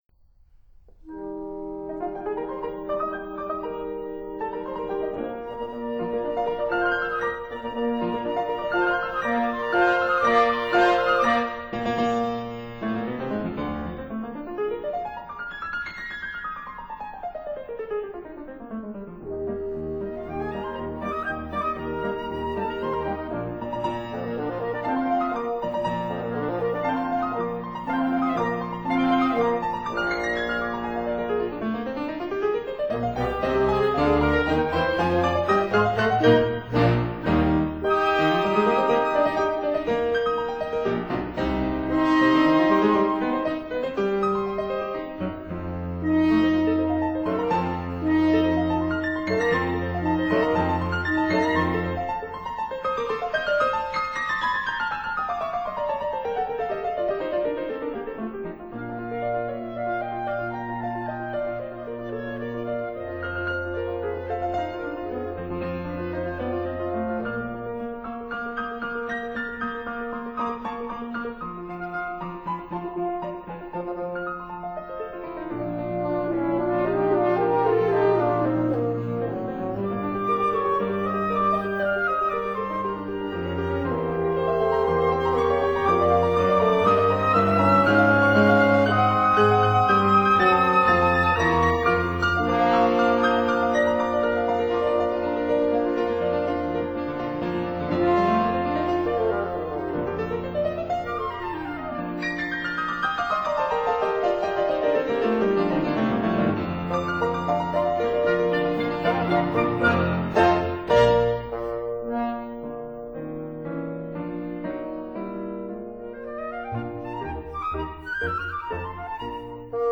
oboe
clarinet
bassoon
double bass
piano
violin
viola
cello